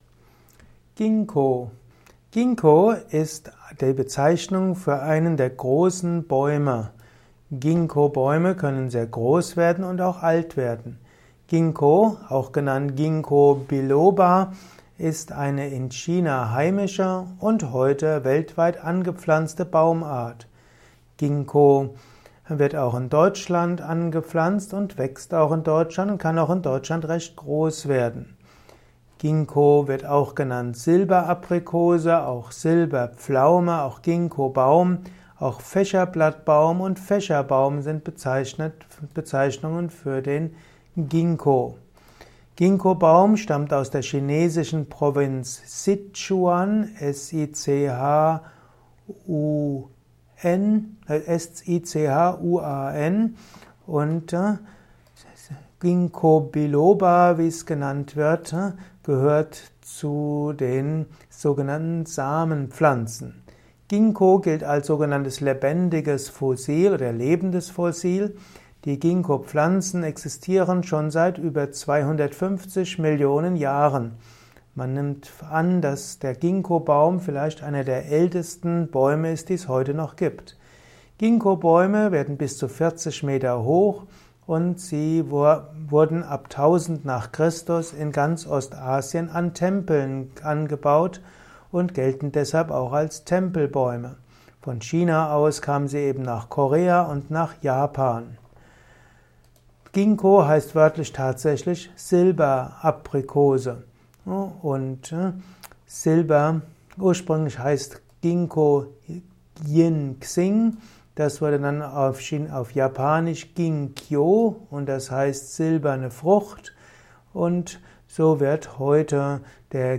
Ein Kurzvortrag über Gingko